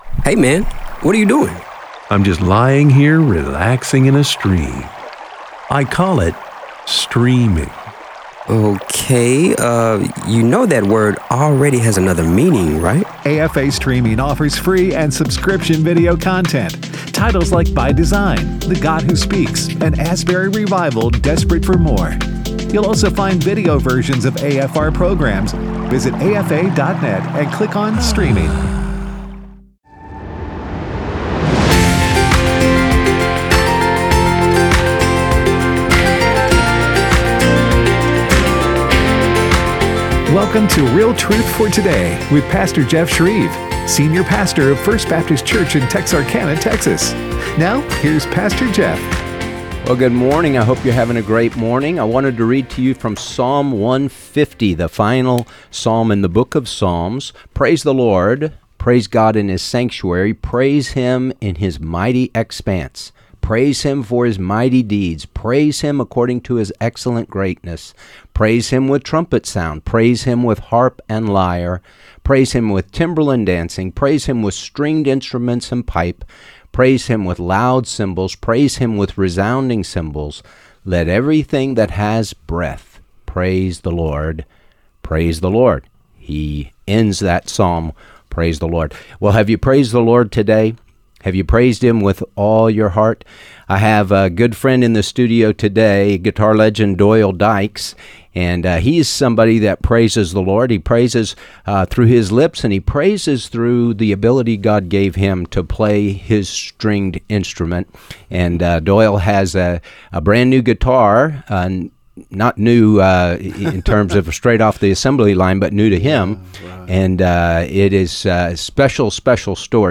Guitarist Doyle Dykes